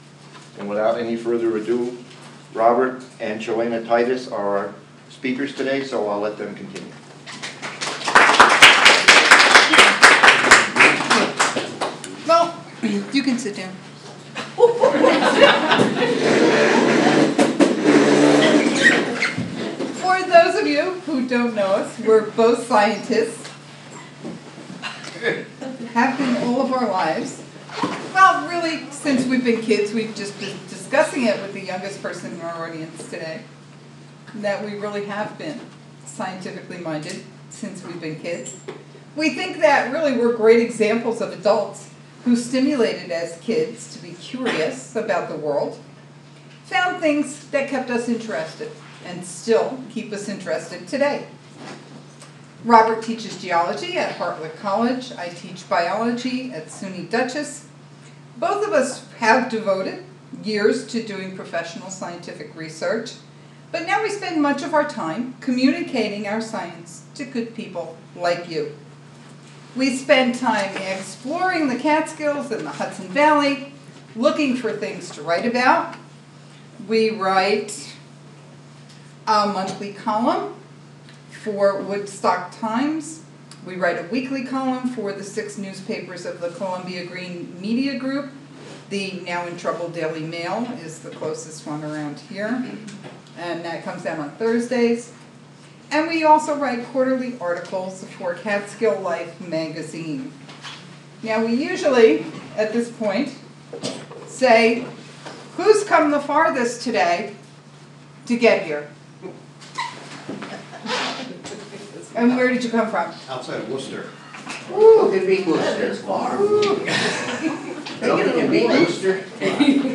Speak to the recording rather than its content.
Cairo Public Library